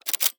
CAMERA_DSLR_Shutter_02_mono.wav